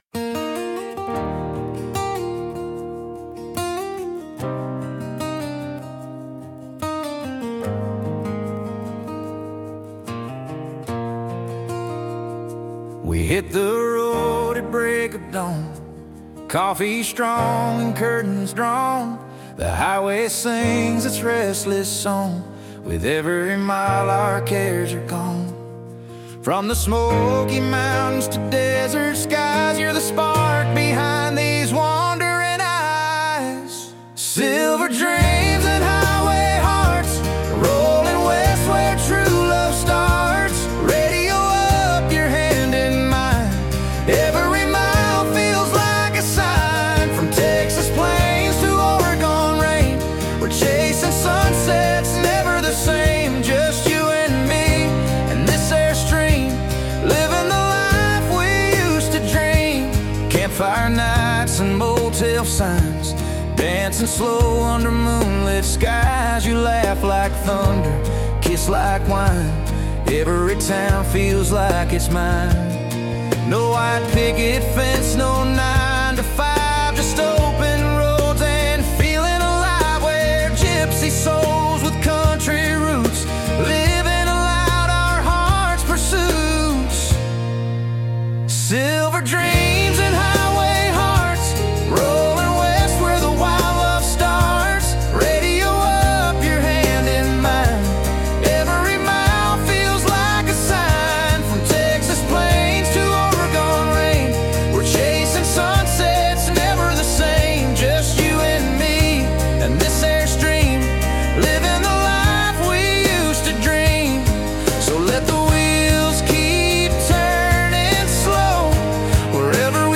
creating original music with the help of artificial intelligence (AI).